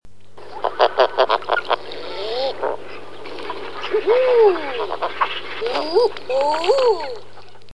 Eider à duvet
Somateria molissima
eider.mp3